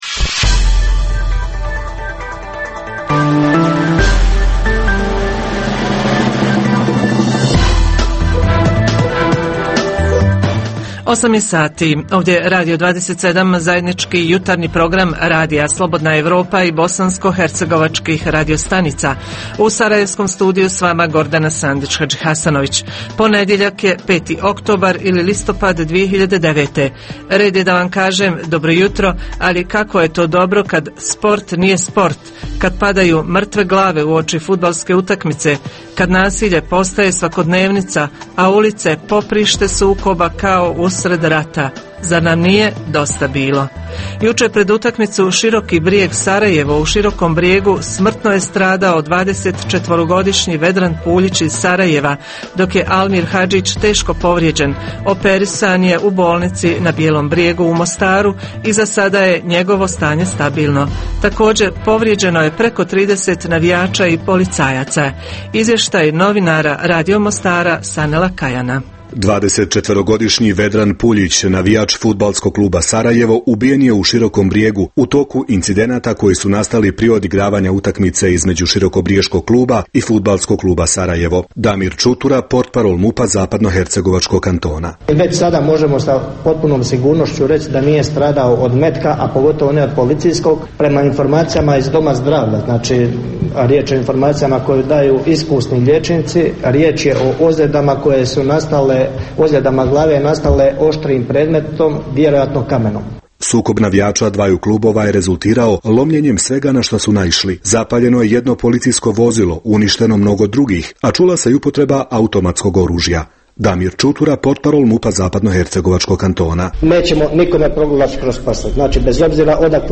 Jutarnji program za BiH koji se emituje uživo. Ponedjeljkom govorimo o najaktuelnijim i najzanimljivijim događajima proteklog vikenda.
Redovni sadržaji jutarnjeg programa za BiH su i vijesti i muzika.